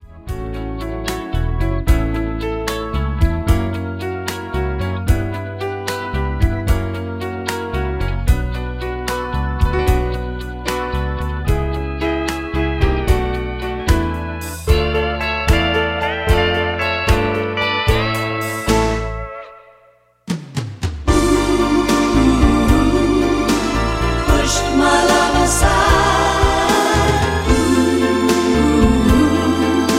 MPEG 1 Layer 3 (Stereo)
Backing track Karaoke
Pop, Musical/Film/TV, 1970s